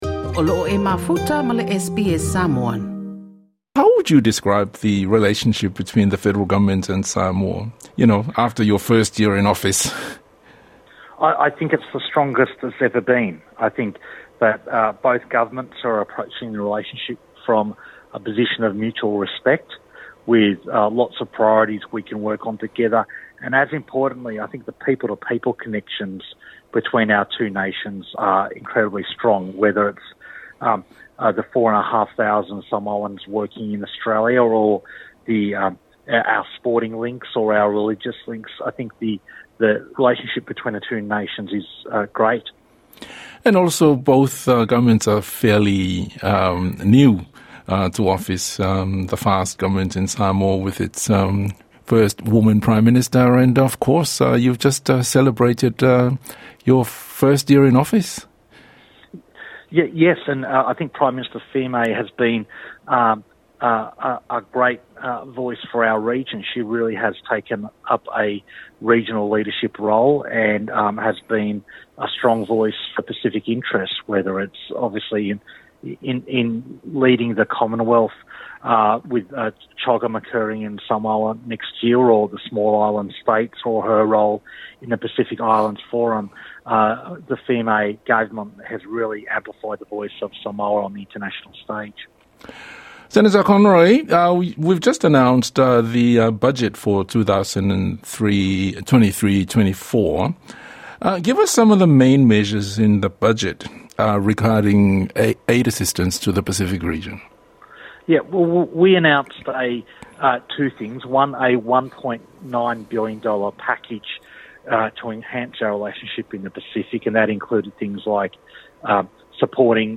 TALANOA: with the minister of International Development and the Pacific, Hon.Pat Conroy.